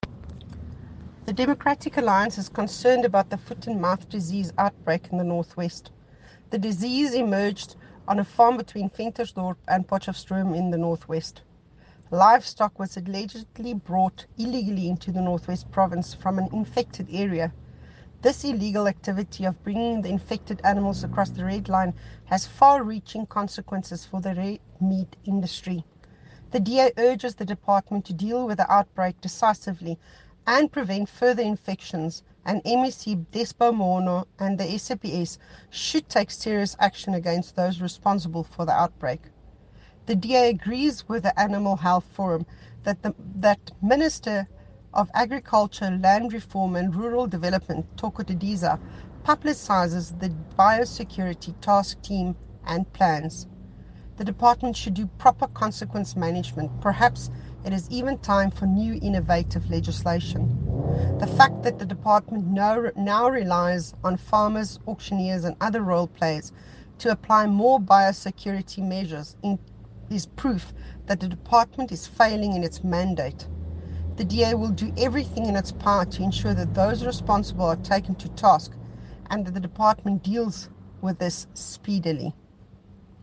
Note to Editors: Please find the attached soundbite in